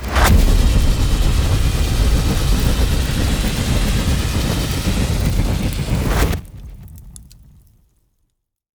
snake-beam.ogg